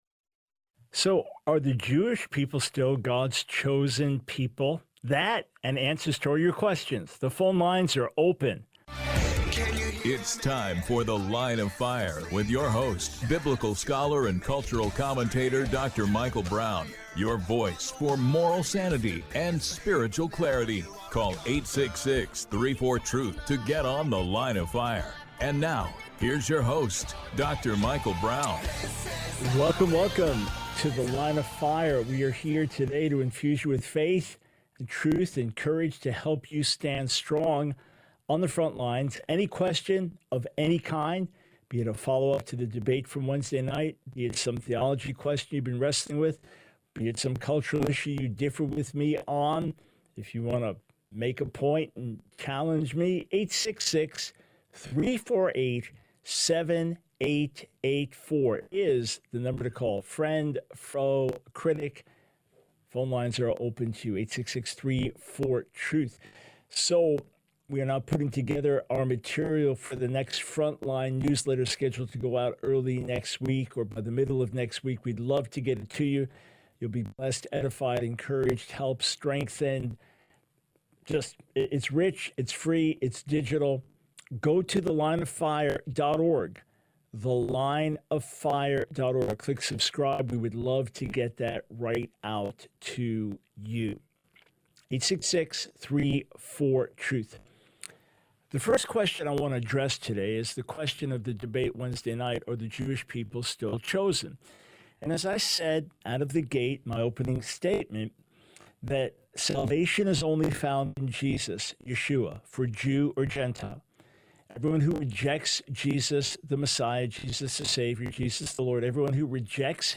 The Line of Fire Radio Broadcast for 07/12/24.